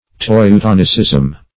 Teutonicism \Teu*ton"i*cism\, n.
teutonicism.mp3